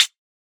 Closed Hats
West MetroHihat (11).wav